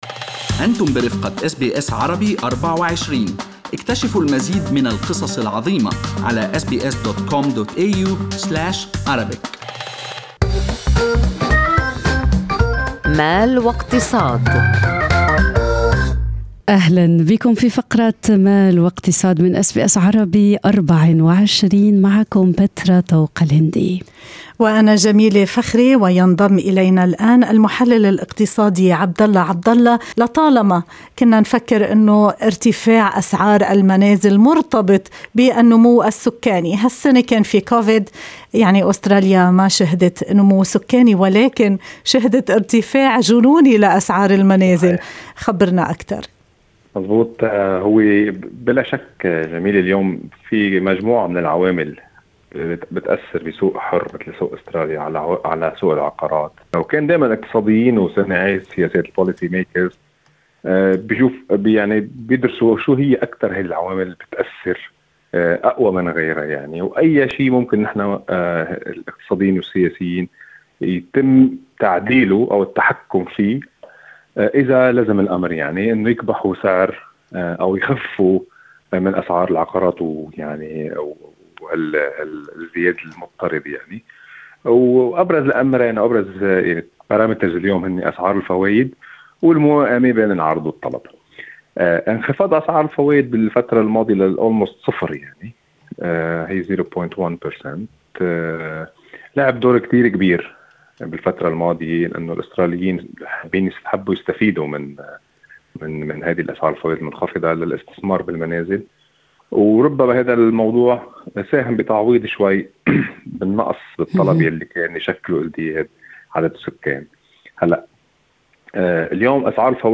في لقاء مع أس بي أس عربي24